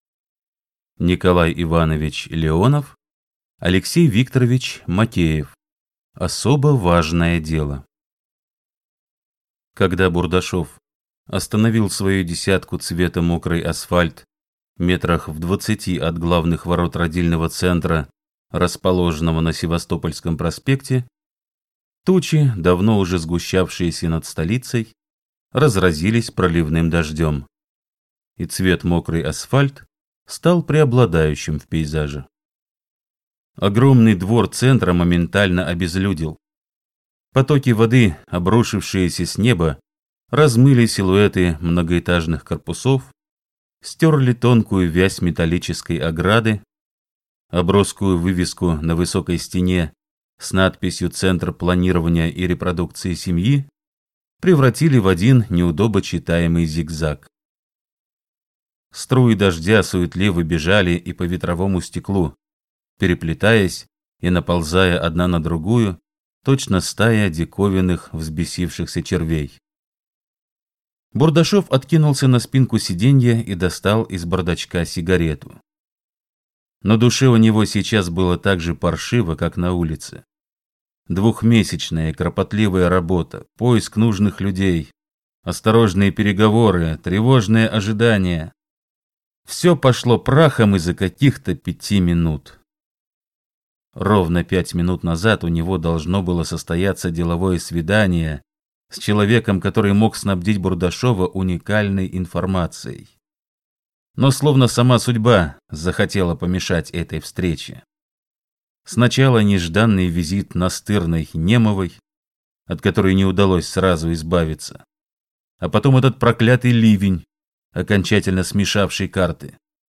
Аудиокнига Особо важное дело | Библиотека аудиокниг
Прослушать и бесплатно скачать фрагмент аудиокниги